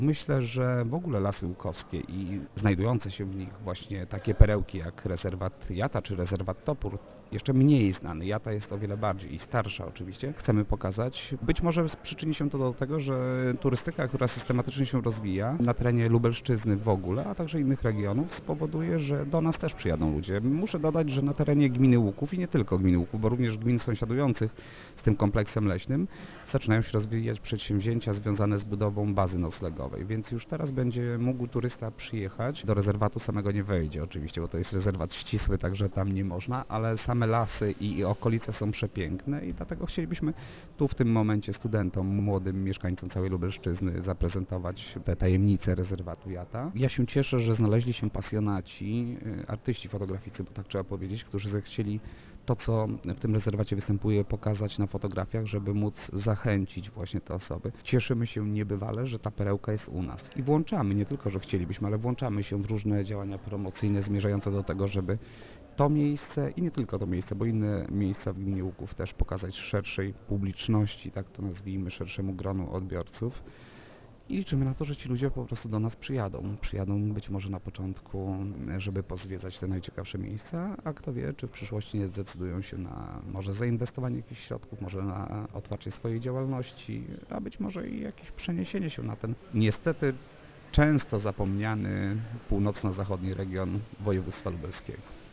W rozmowie z Informacyjną Agencją Samorządową wójt gminy Łuków Mariusz Osiak przypomina, że starszy od "Jaty", która została utworzona w 1933 roku, jest tylko Białowieski Park Narodowy: